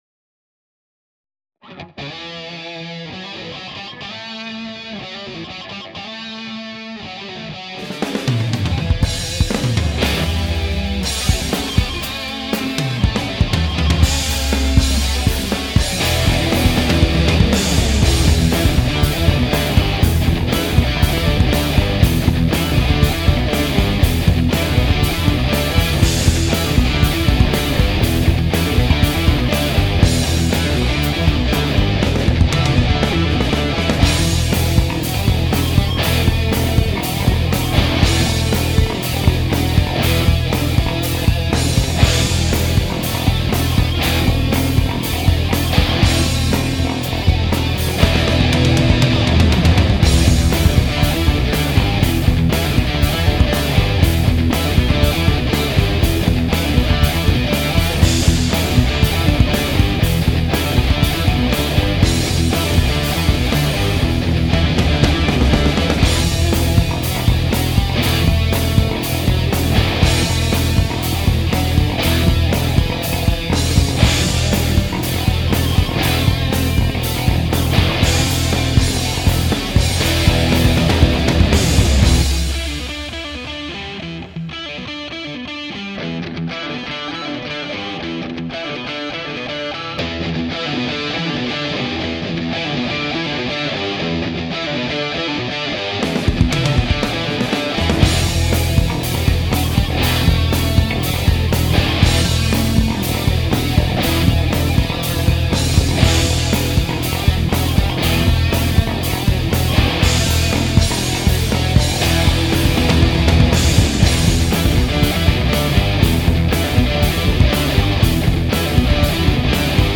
My first try of home recording
So that my playmates will fall on their as* I have Lenovo, Nuendo4 ,EZdrummer2,digitech rp255,EZkeys,EZmix and for master i use either Ozone5 or EZmix. I record guitars and even a bass guitar with Gibson SG since i don't have a bass guitar.